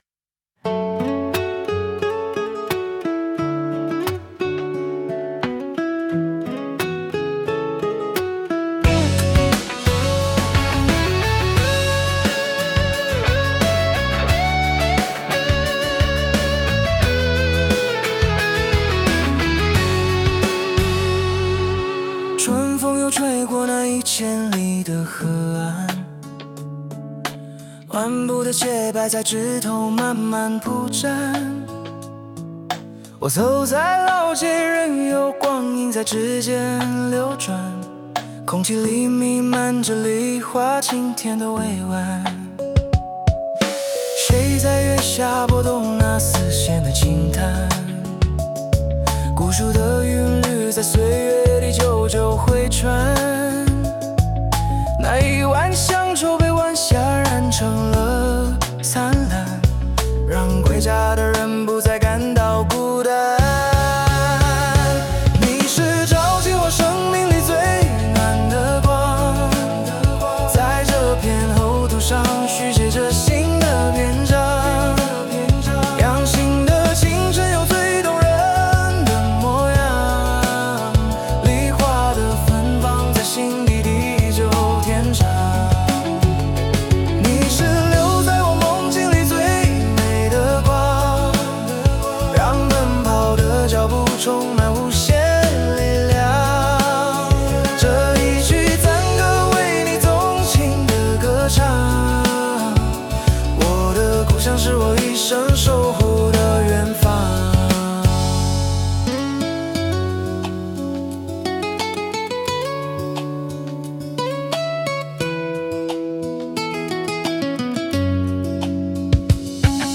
梨乡谣 (男声)